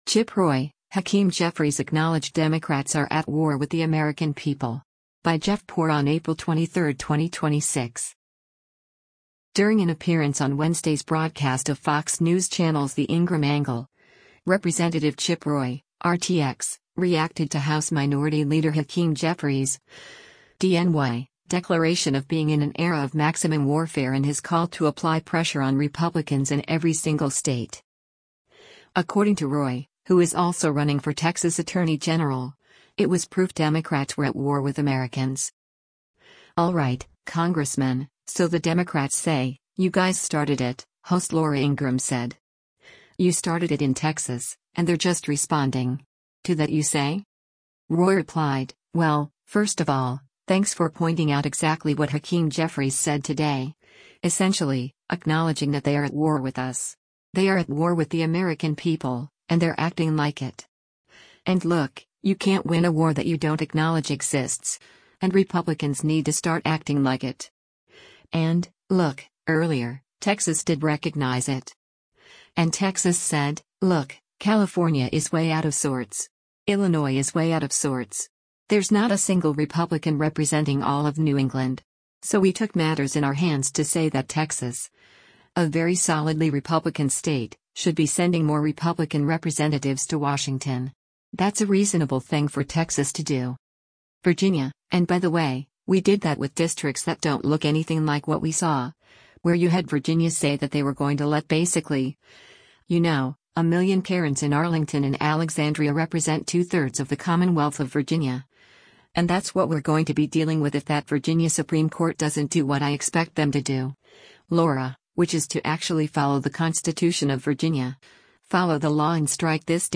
During an appearance on Wednesday’s broadcast of Fox News Channel’s “The Ingraham Angle,” Rep. Chip Roy (R-TX) reacted to House Minority Leader Hakeem Jeffries (D-NY) declaration of being in an “era of maximum warfare” and his call to apply “pressure on Republicans in every single state.”